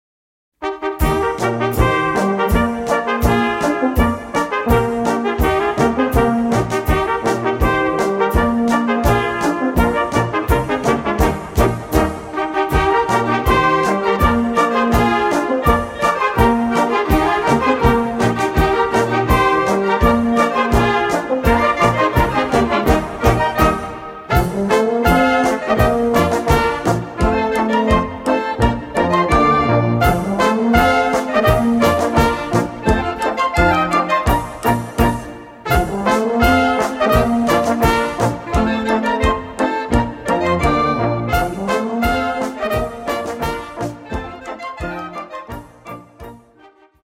Gattung: für Kleine Besetzung
Besetzung: Kleine Blasmusik-Besetzung